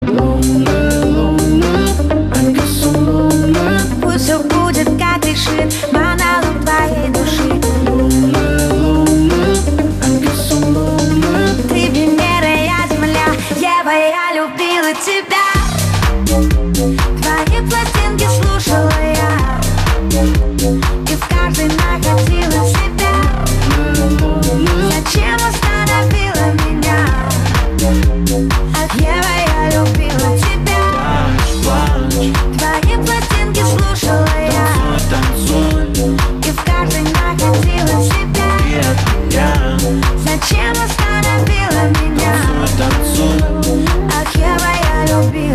• Качество: 320, Stereo
поп
Bass House
Концертная запись. Live